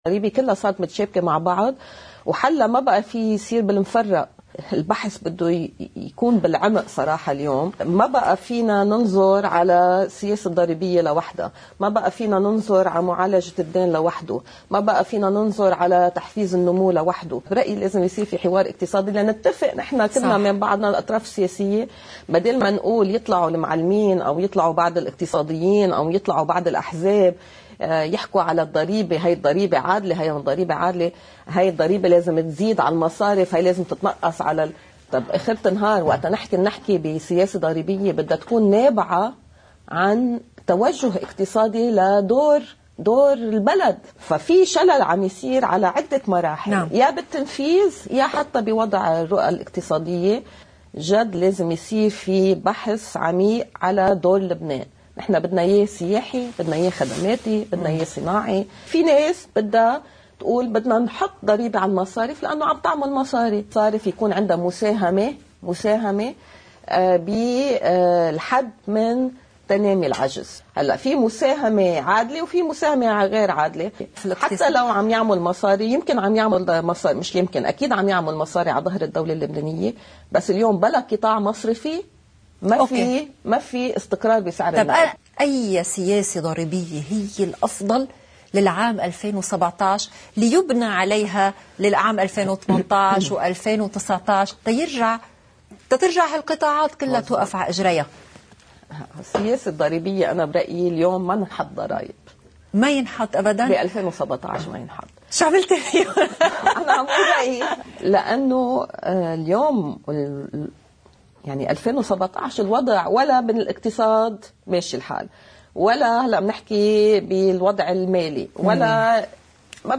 مقتطف من حديث ريّا الحسن (وزيرة المال السابقة) لقناة الـ”LBC”: